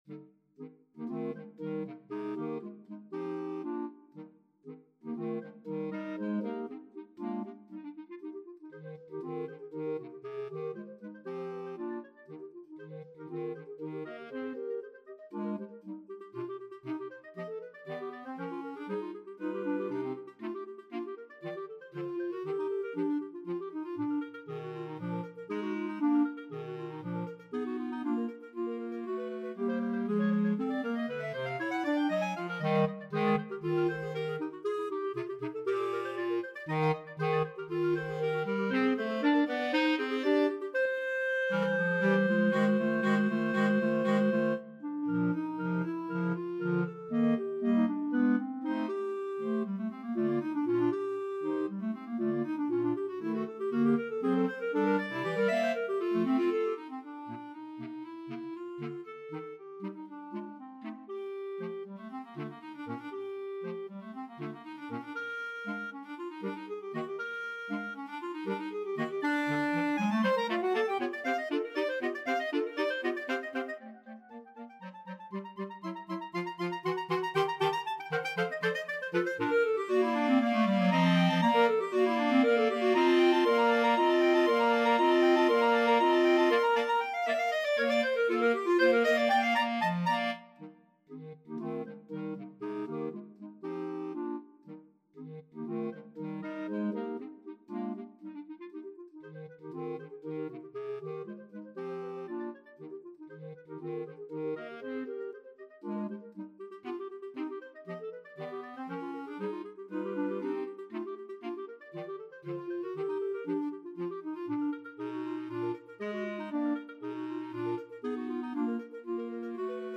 Free Sheet music for Clarinet Quartet
Clarinet 1Clarinet 2Clarinet 3Bass Clarinet
2/4 (View more 2/4 Music)
Eb major (Sounding Pitch) F major (Clarinet in Bb) (View more Eb major Music for Clarinet Quartet )
Allegro giusto = 118 (View more music marked Allegro)
Clarinet Quartet  (View more Advanced Clarinet Quartet Music)
Classical (View more Classical Clarinet Quartet Music)